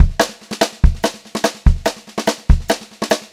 144SPBEAT2-L.wav